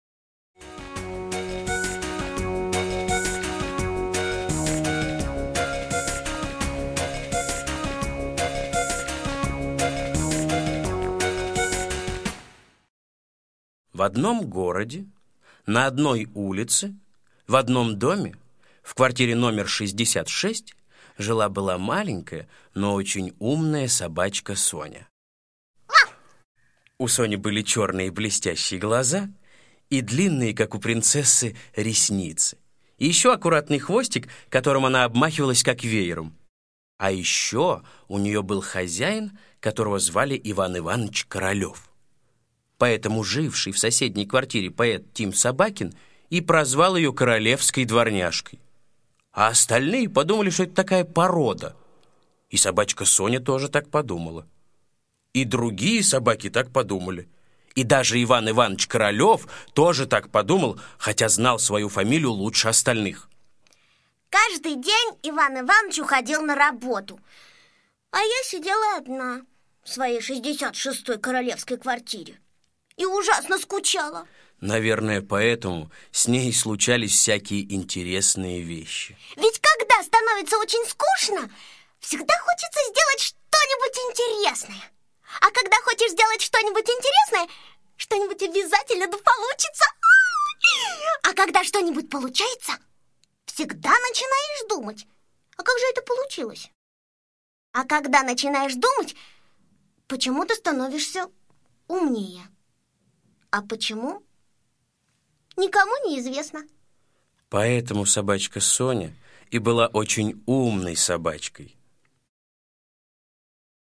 Королевская дворняжка - аудиосказка Усачева А.А. Про собачку Соню, которая жила в 66 квартире, а сосед прозвал ее Королевской дворняжкой.